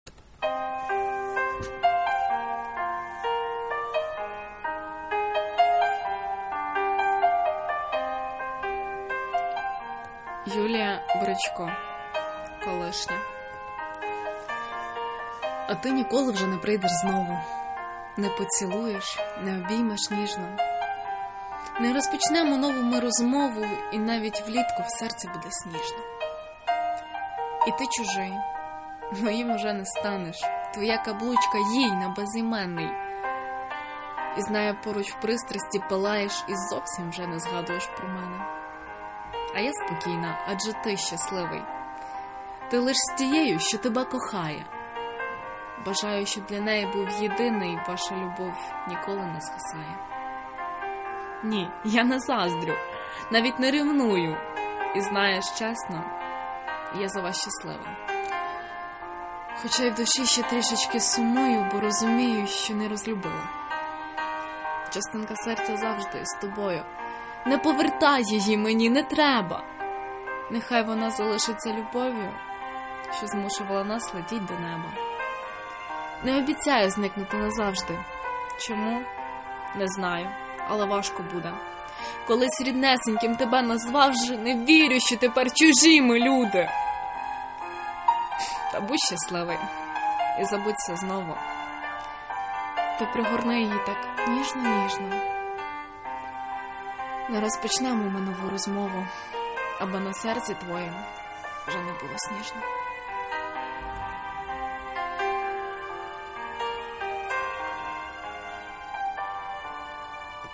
голос красивый и слова классные.